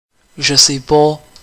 The "Quebec" audio samples here are not necessarily from speakers of Quebec French, which has distinct regional pronunciations of certain words.
English French Quebec accent French accent
[ʒə (nə) se pɔ] [ʒə (nə) pɑ]